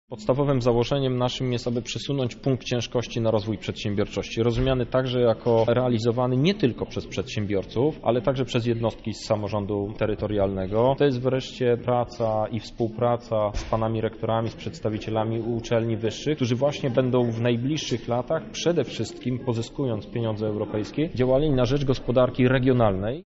Wszystkim nam zależy na jak najlepszym efekcie wykorzystania tych środków – mówi Marszałek Województwa – Krzysztof Hetman